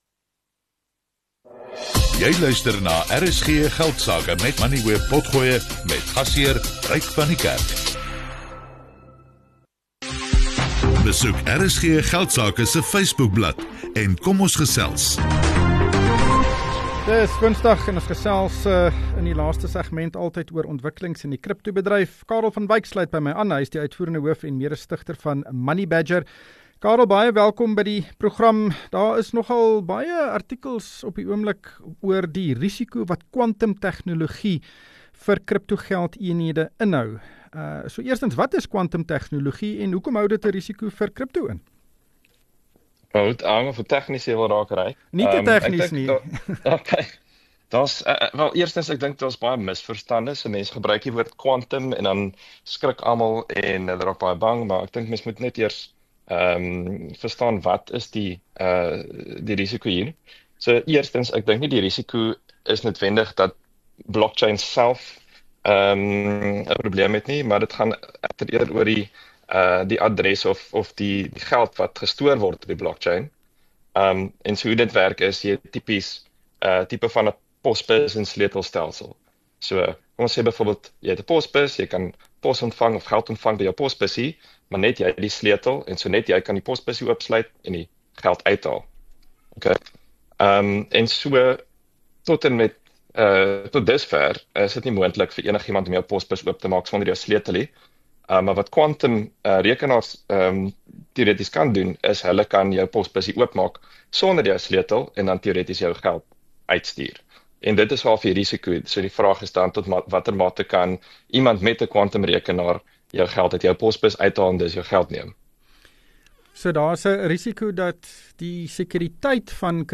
RSG Geldsake is die grootste sakeprogram in Suid Afrika. Dit fokus op belangrike sakenuus, menings en beleggingsinsigte. RSG Geldsake word elke weeksdag tussen 18:10 en 19:00 op RSG (101 – 104 FM) uitgesaai.